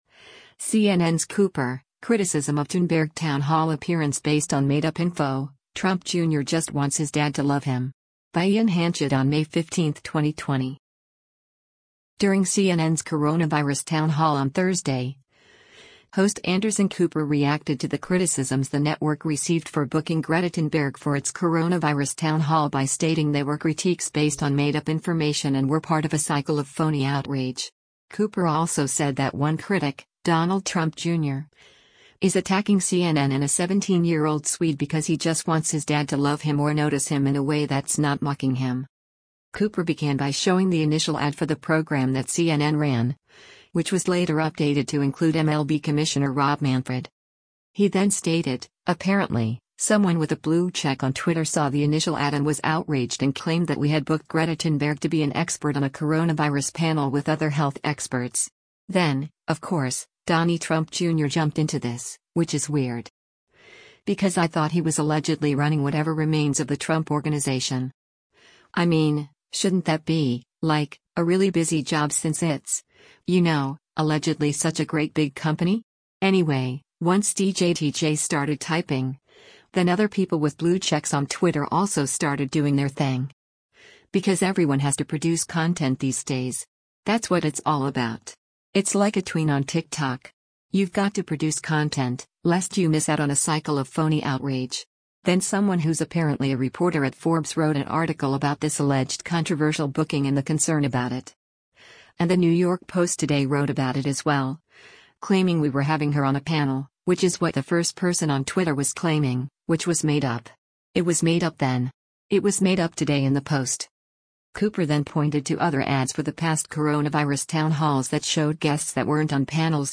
During CNN’s coronavirus town hall on Thursday, host Anderson Cooper reacted to the criticisms the network received for booking Greta Thunberg for its coronavirus town hall by stating they were critiques based on “made up” information and were part of “a cycle of phony outrage.”